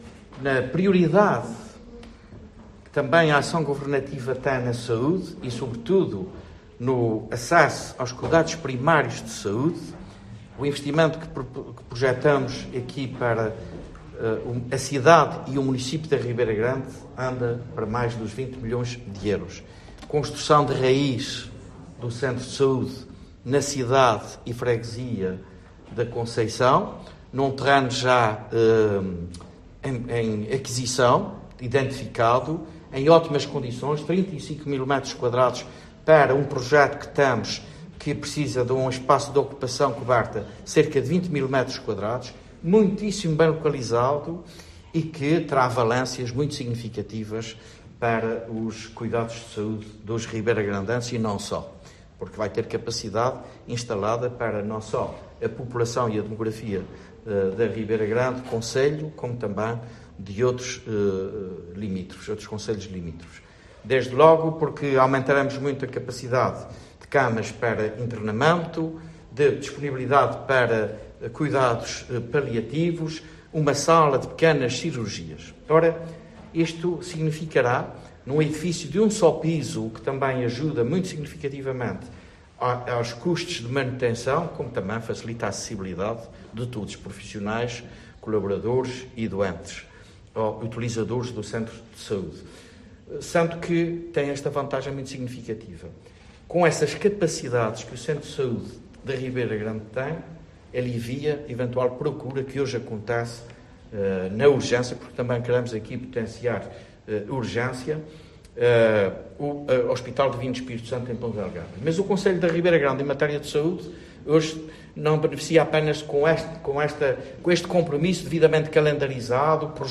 José Manuel Bolieiro spoke at the Ribeira Grande Town Hall, where the land where the new centre will be located was presented via computer - due to the heavy rain, it was not possible to visit the site today.